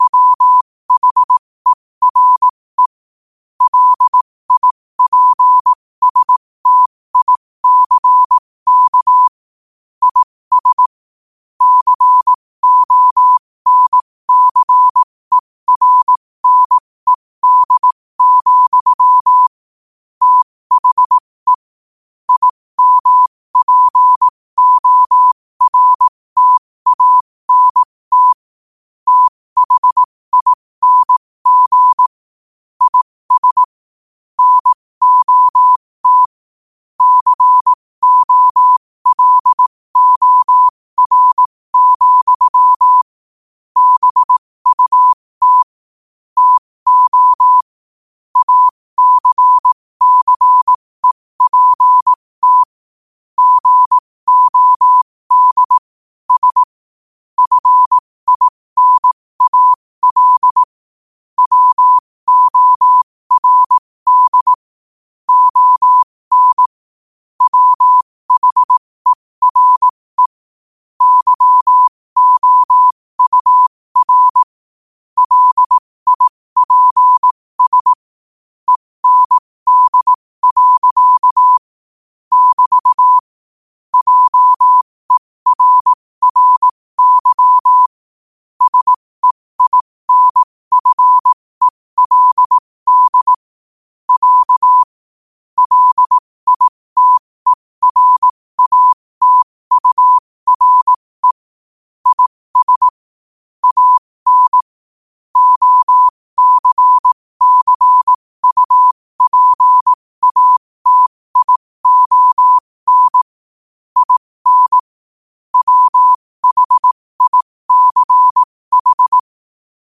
New quotes every day in morse code at 15 Words per minute.